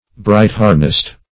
Search Result for " bright-harnessed" : The Collaborative International Dictionary of English v.0.48: Bright-harnessed \Bright"-har`nessed\, a. Having glittering armor.
bright-harnessed.mp3